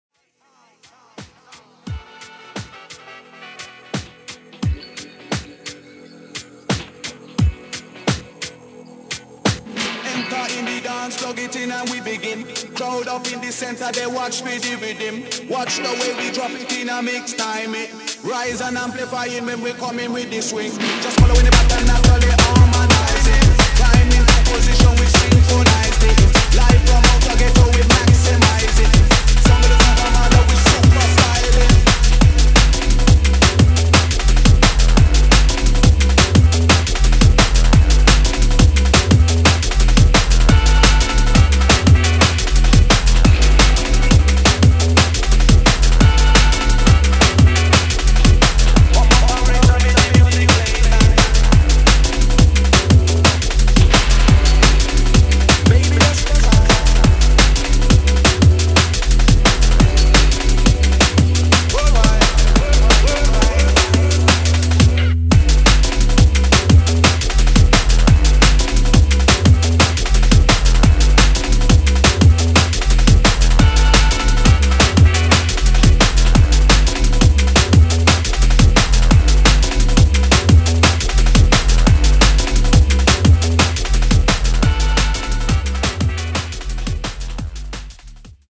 [ DUB / DRUM'N'BASS ]